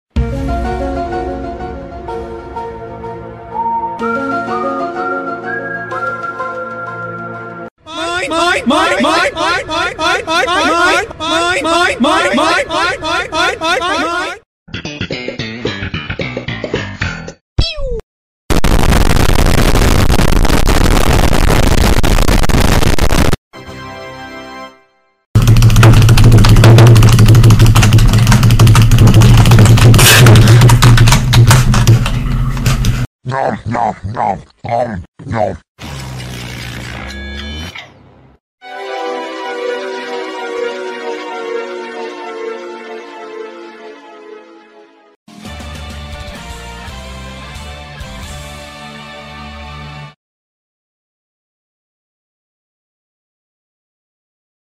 10+ Viral Sound Effects for sound effects free download